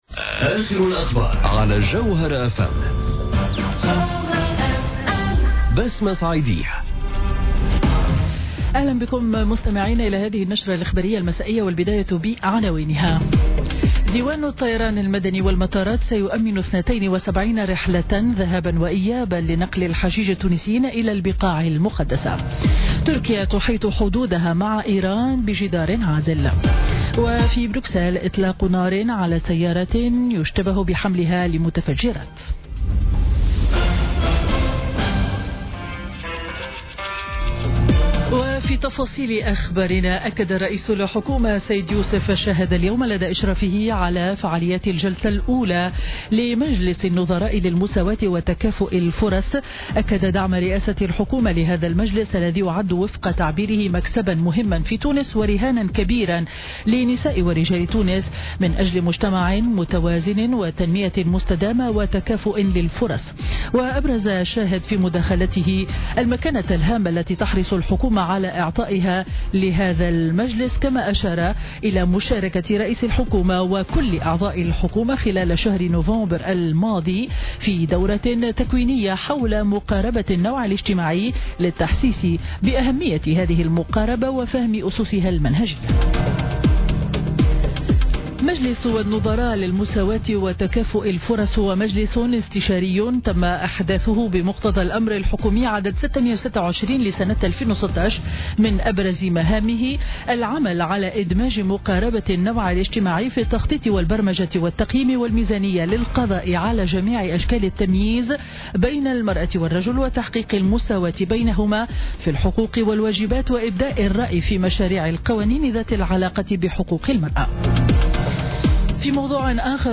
نشرة أخبار السابعة مساء ليوم الثلاثاء 8 أوت 2017